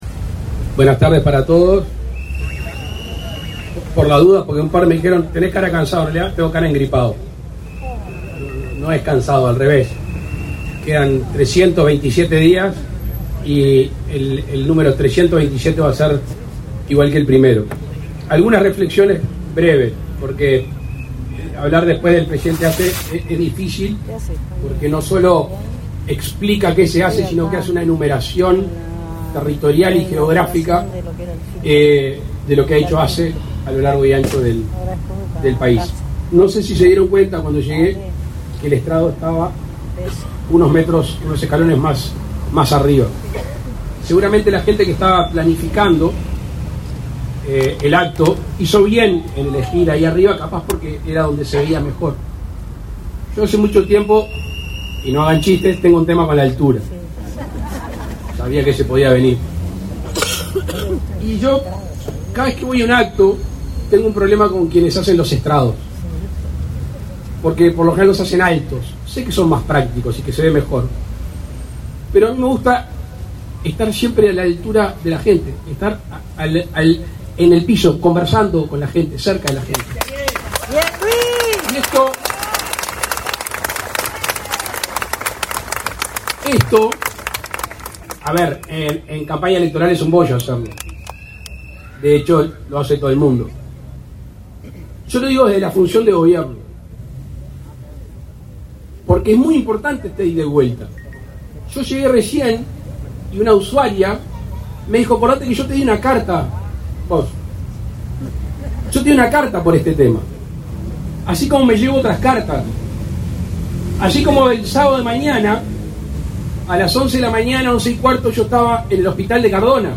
Palabras del presidente de la República, Luis Lacalle Pou
Con la presencia del presidente de la República, Luis Lacalle Pou, este 8 de abril, fueron inauguradas las obras realizadas por ASSE en el hospital